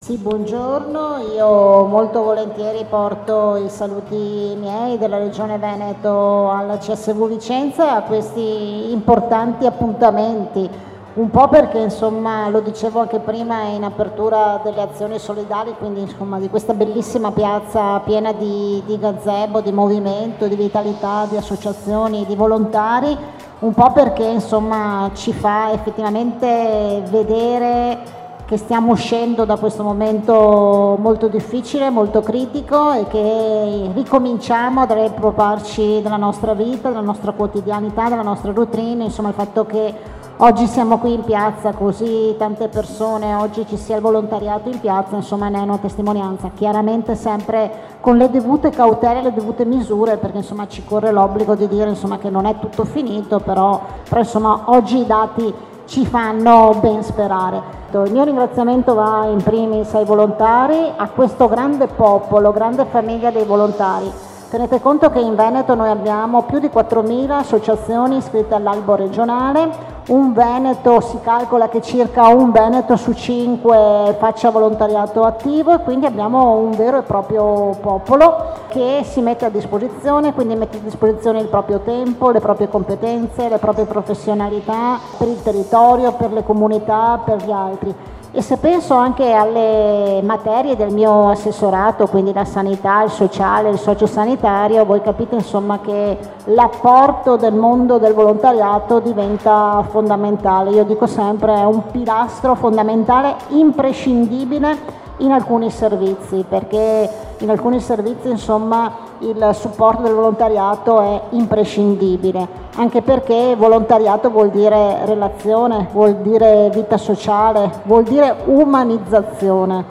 AZIONI SOLIDALI – INTERVISTE
“Pilastro fondamentale e imprescindibile per l’umanizzazione”  è così che l’Assessore alle Politiche Sociali e alla Sanità della Regione Veneto Manuela Lanzarin definisce il volontariato durante la sua entusiasta partecipazione ad Azioni Solidali Vicentine 21a edizione, riproponiamo qui la sua dichiarazione iniziale.